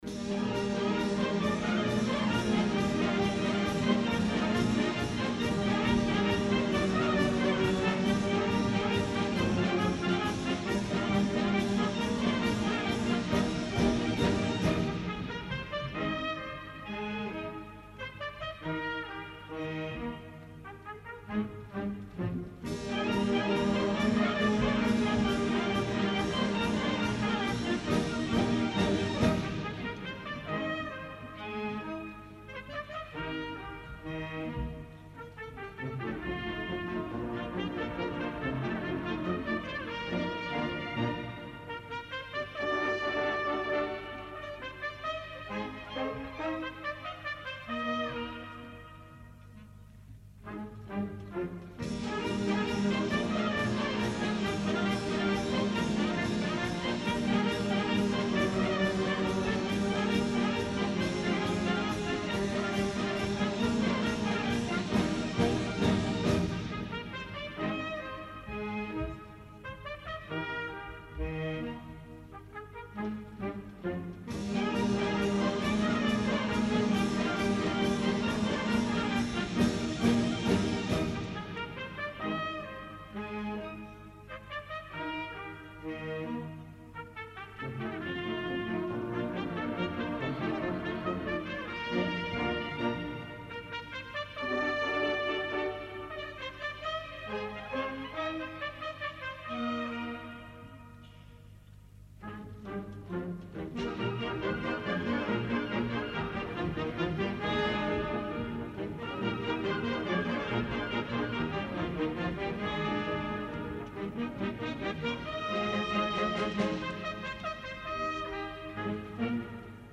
Concert de Santa Cecília a l'Esglèsia de la Nostra Senyora de la Consolació